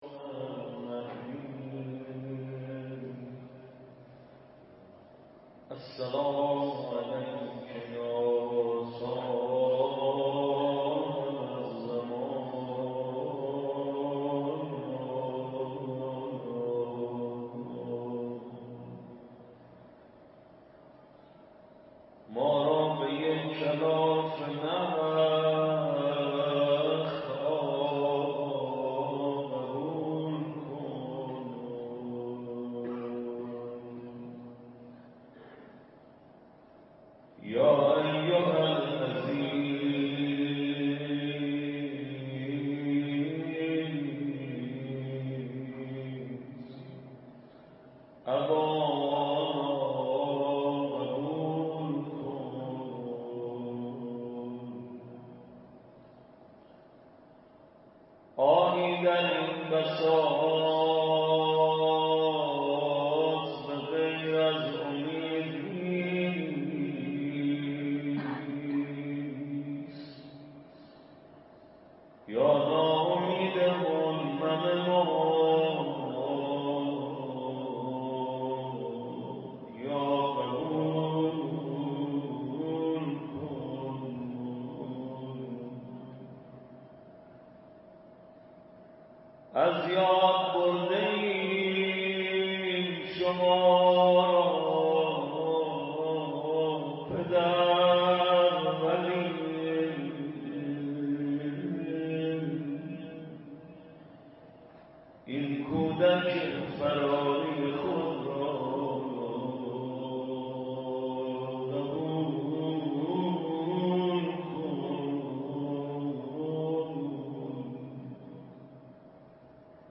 مراسم توسل به مناسبت شهادت حضرت هادی علیه‌السلام